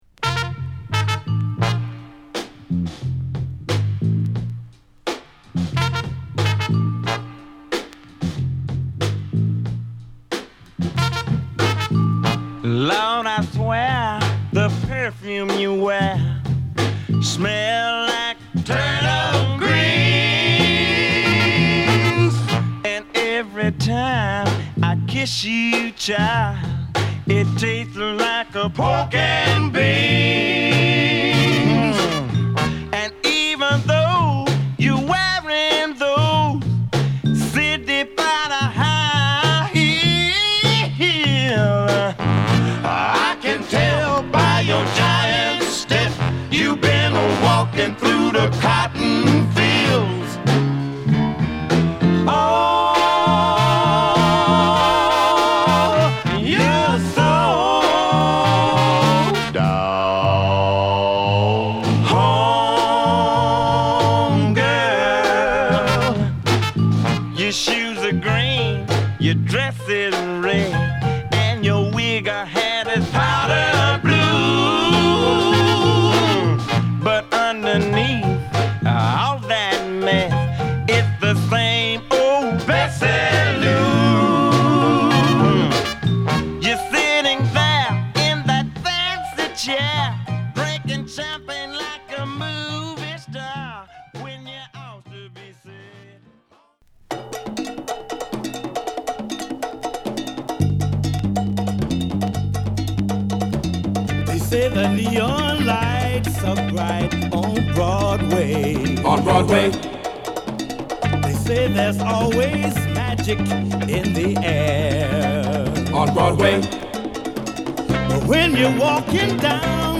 ＊B3途中で数回プチノイズ入ります。